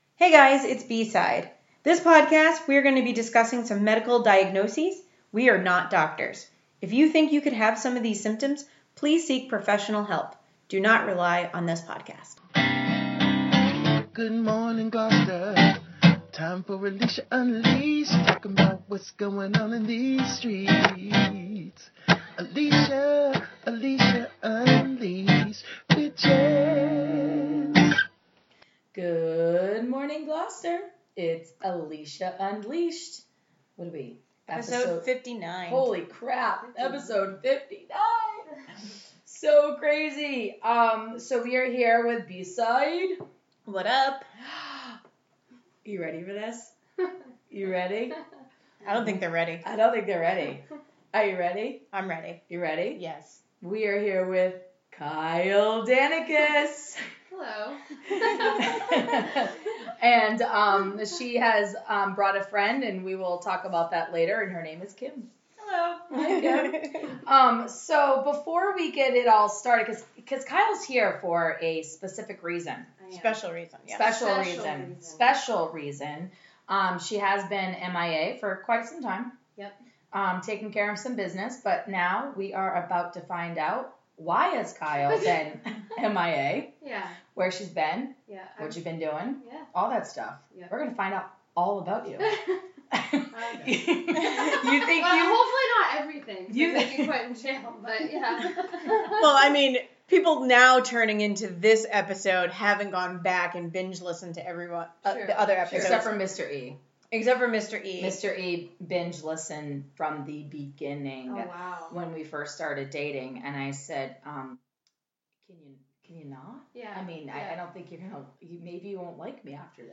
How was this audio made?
Also we apologize for the recording quality and echo…and the crunching.